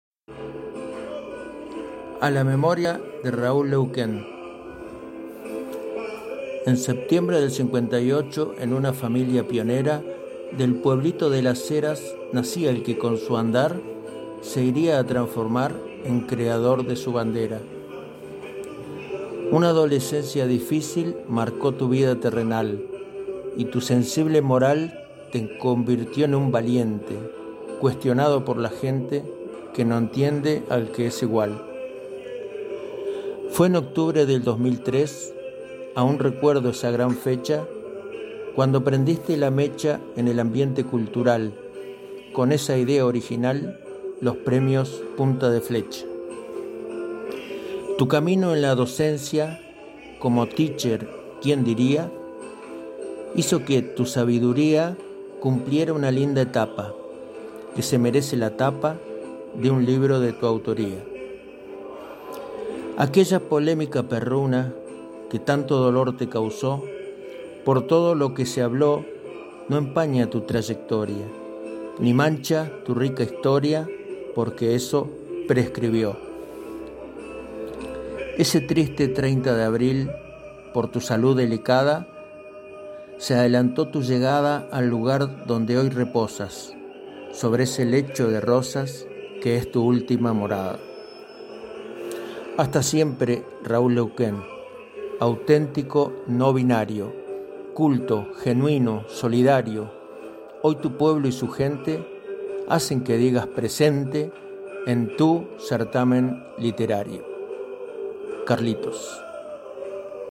EL AUDIO DE LA POESIA EN LA VOZ  DE  SU AUTOR